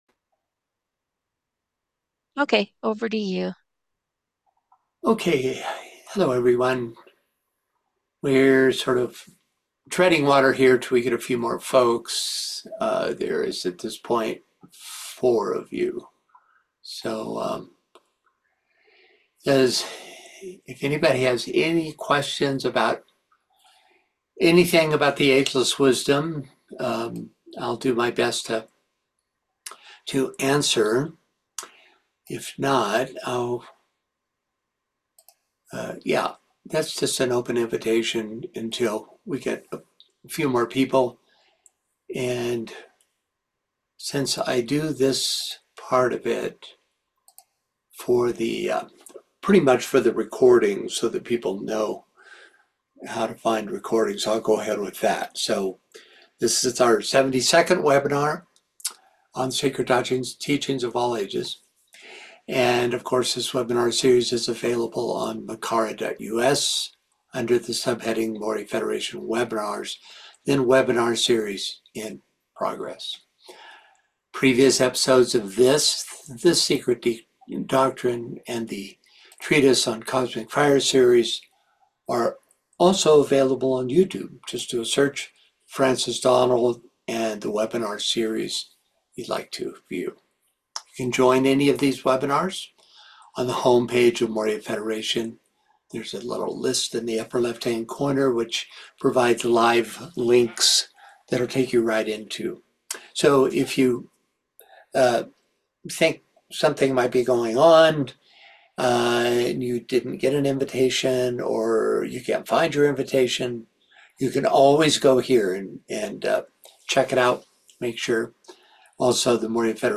Secret Teachings of All Ages webinars